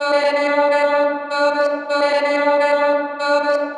• chopped vocals 109-127 female 1 (4) - Dm - 128.wav